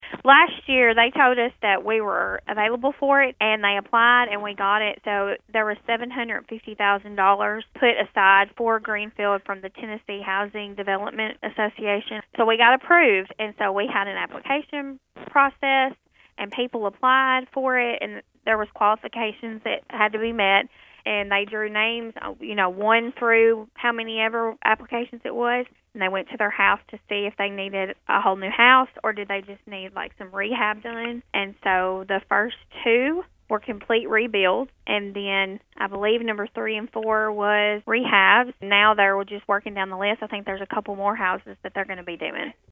Greenfield City Recorder Jerica Spikes told Thunderbolt News about the process of getting the grant.